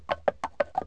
horse3.wav